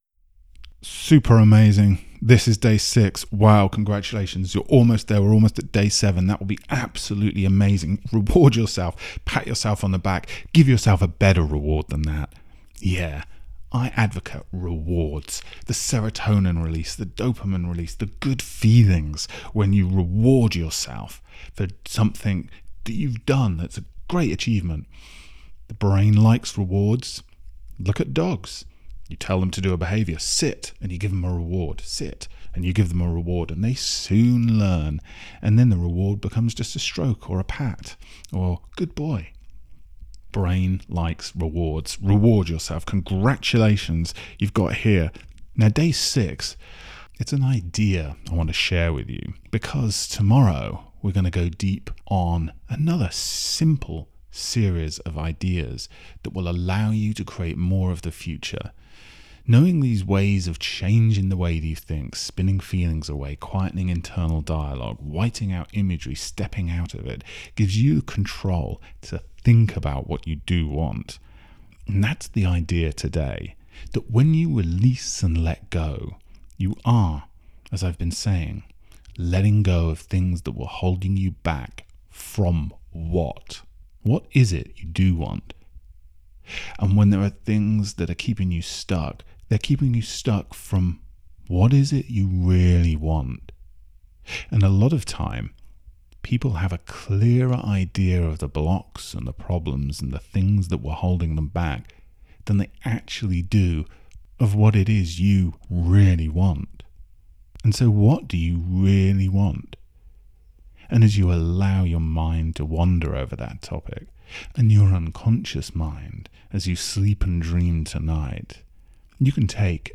Its best to slip on a pair of headphones... get yourself comfortable, press play and relax with this 11 minute Hypnotic PowerNap for Releasing & Letting Go